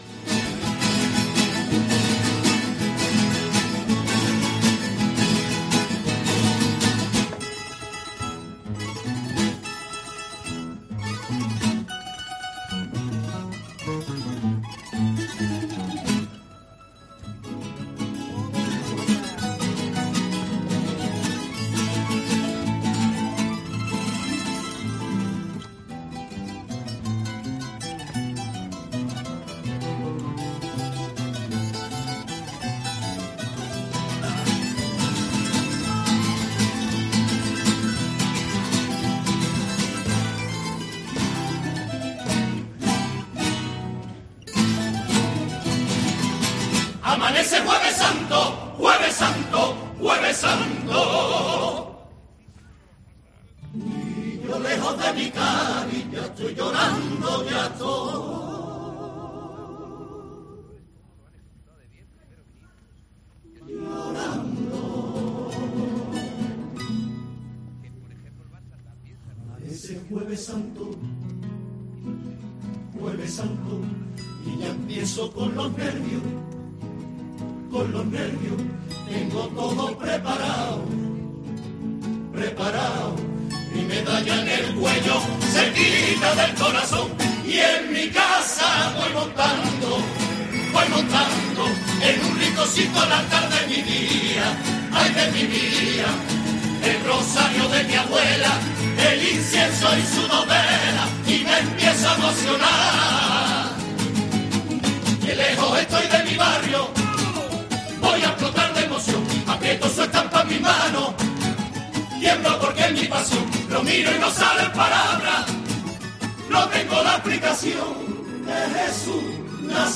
Tango
Carnaval